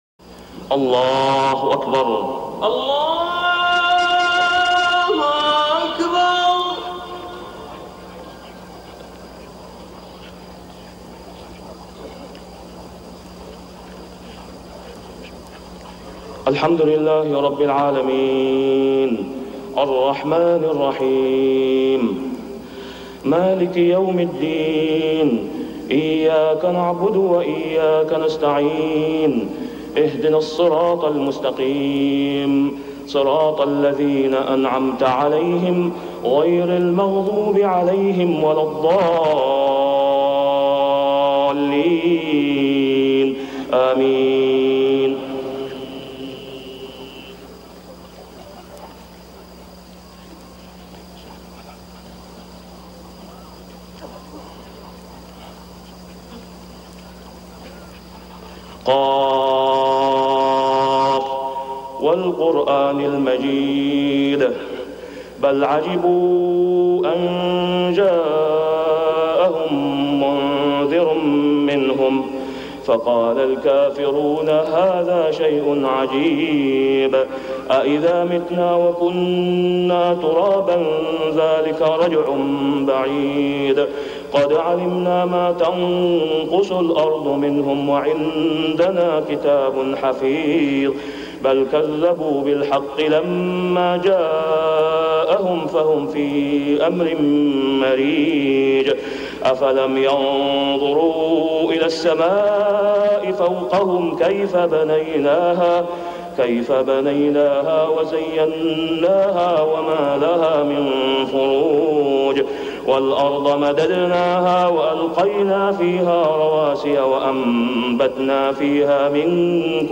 صلاة الفجر ذوالقعدة 1421هـ سورة ق كاملة > 1421 🕋 > الفروض - تلاوات الحرمين